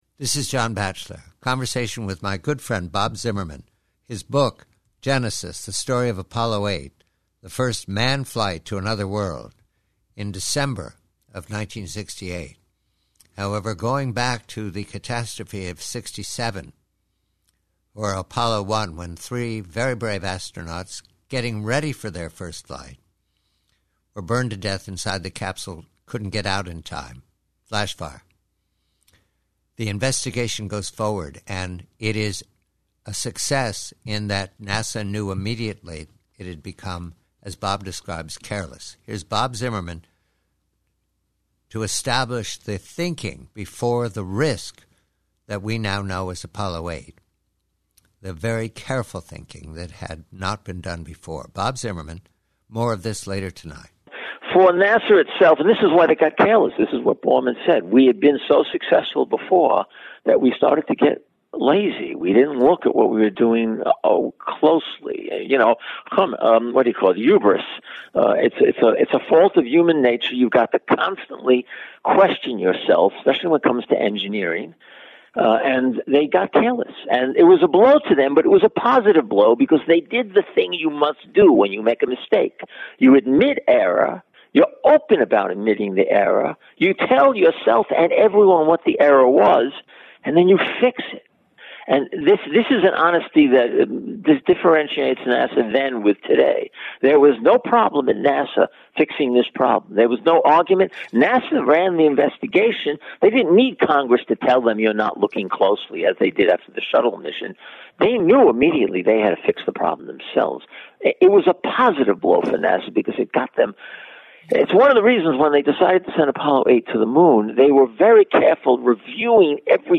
Conversation excerpt